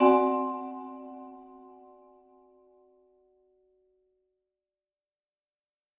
Category: Percussion Hits
Vibraphone-Hit-2.wav